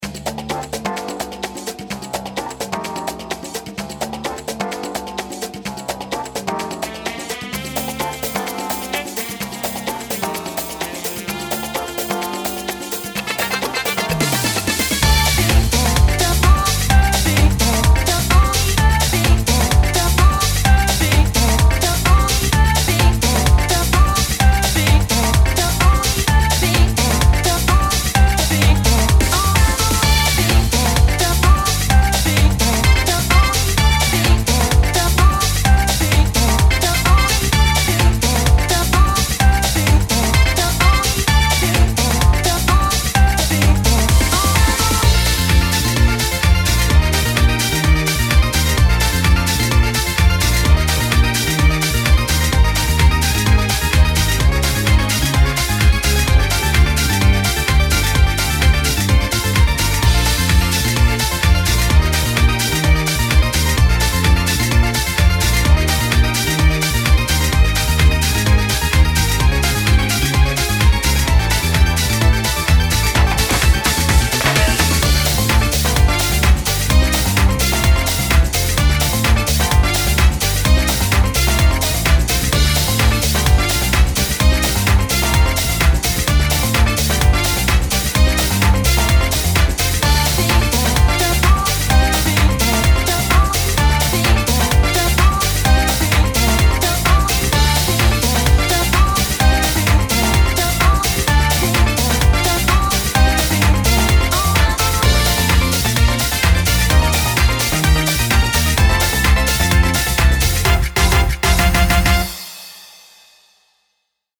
BPM128
Audio QualityPerfect (High Quality)
funky beat